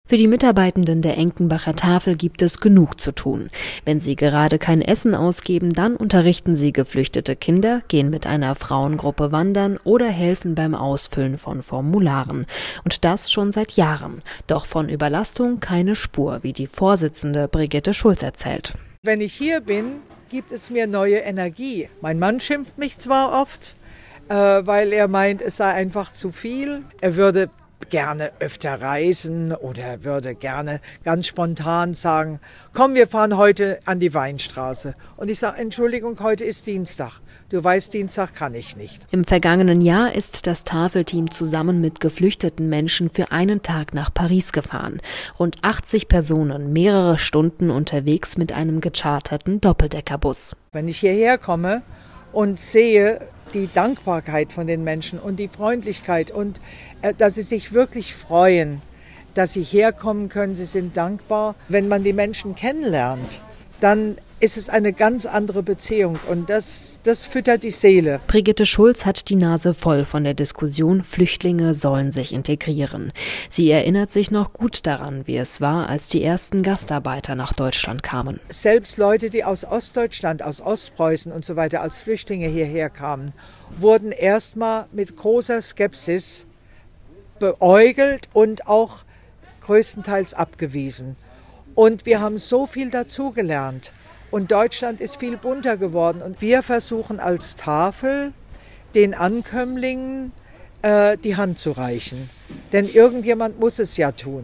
Interview zum Weltflüchtlingstag